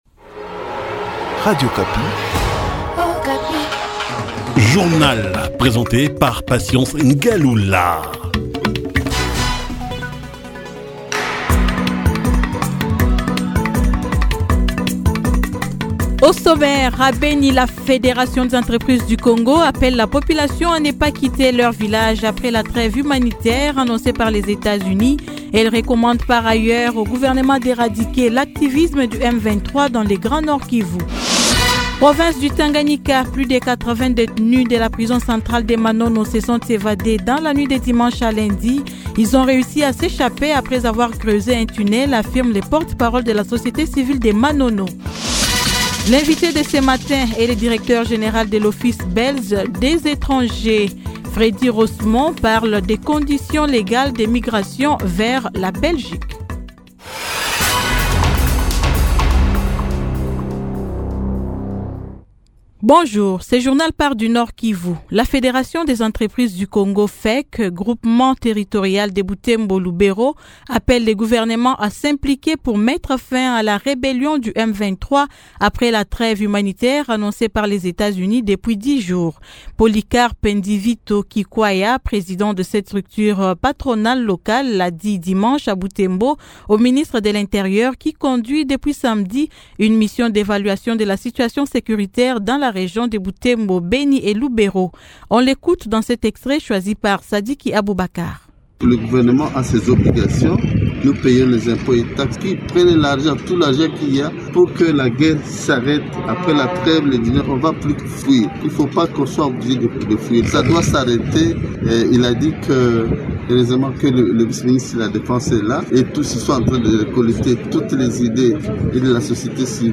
Journal matin 08H
Reportage.